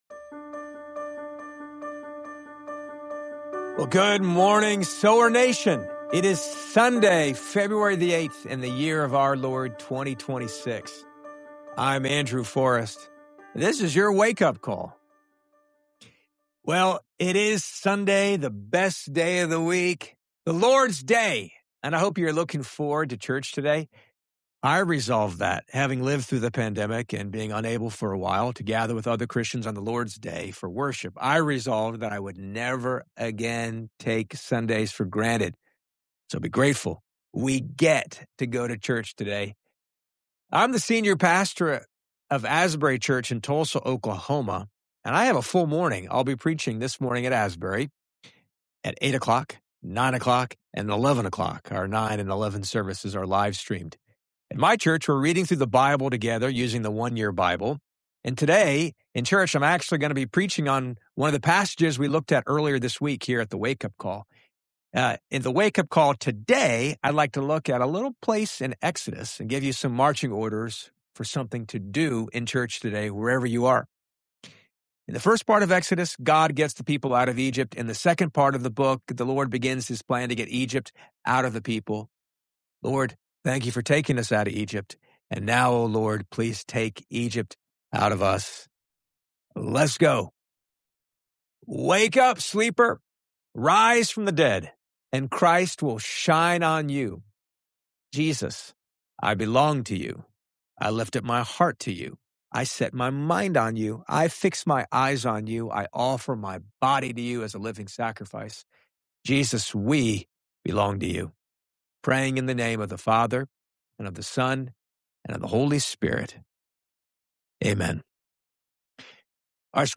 A moving rendition of the hymn “I Need Thee Every Hour,” with a reminder of our daily dependence on grace.